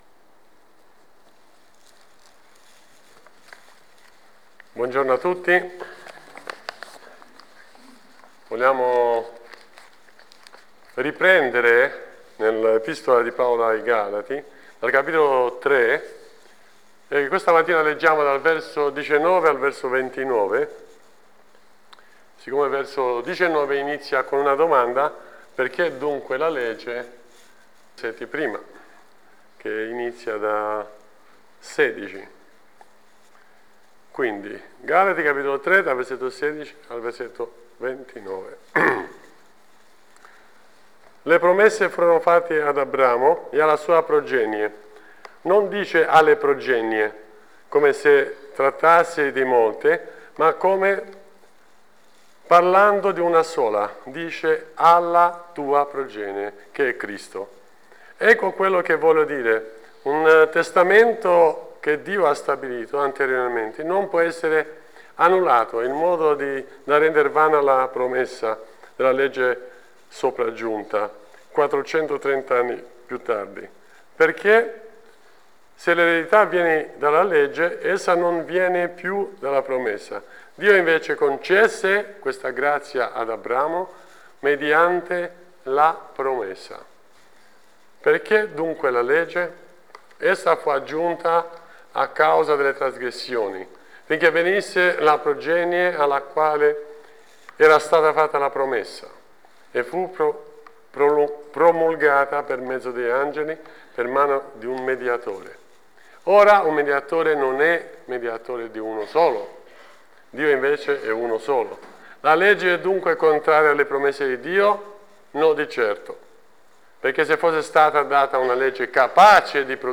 Insegnamenti sul passo di Galati 3:19-29.